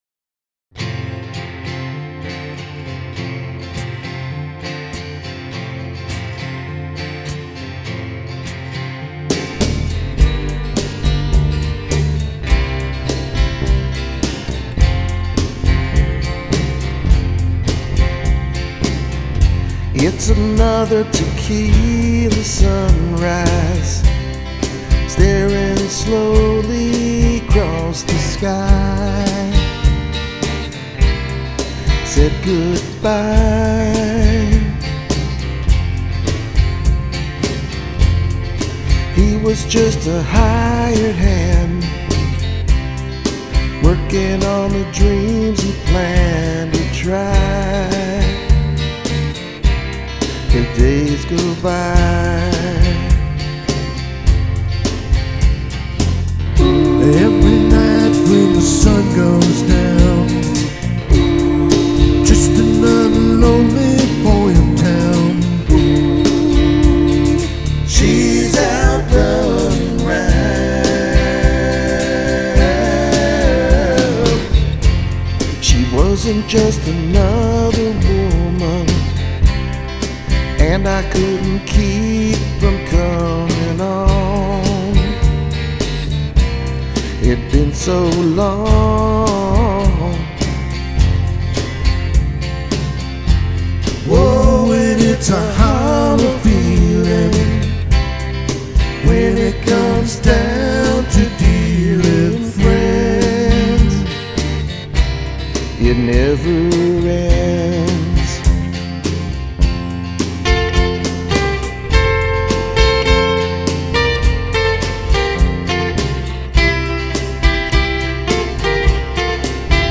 Bass
Lead Guitar/Vocals
Drums